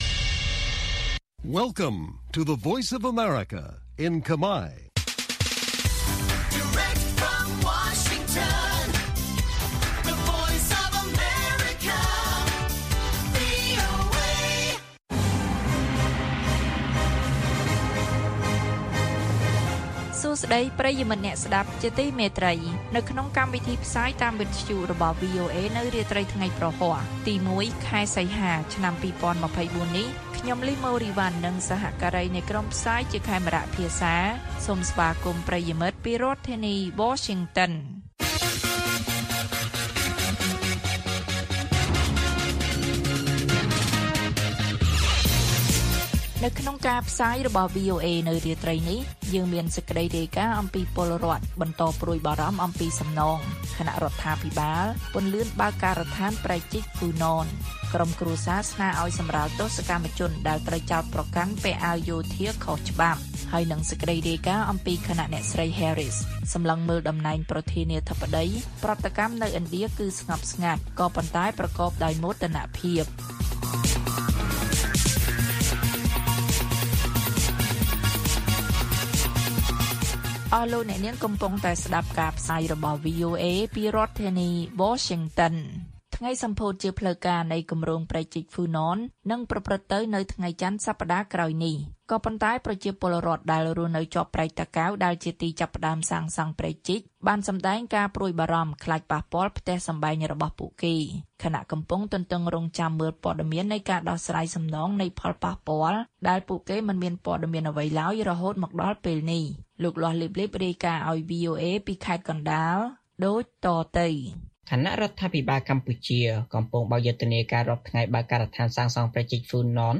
ព័ត៌មានពេលរាត្រី ១ សីហា៖ ពលរដ្ឋបន្តព្រួយបារម្ភអំពីសំណង ខណៈរដ្ឋាភិបាលពន្លឿនបើកការដ្ឋានព្រែកជីកហ្វូណន